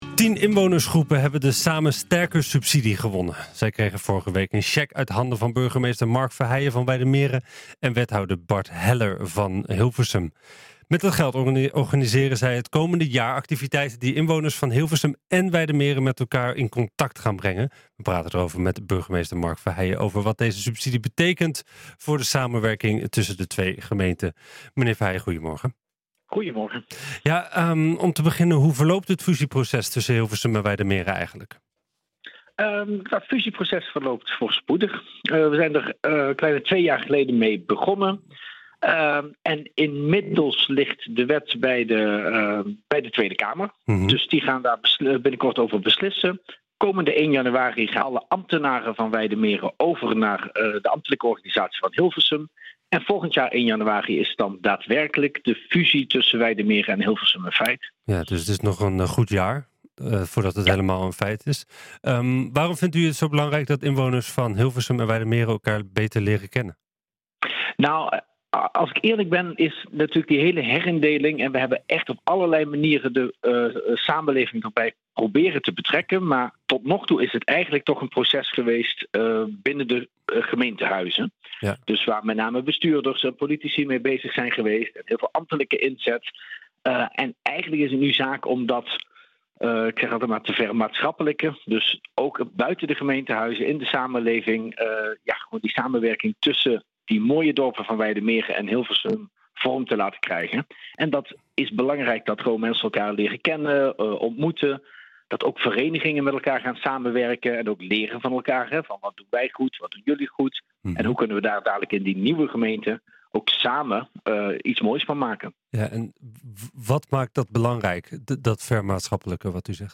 We praten met burgemeester Mark Verheijen over wat deze subsidie betekent voor de samenwerking tussen de twee gemeenten.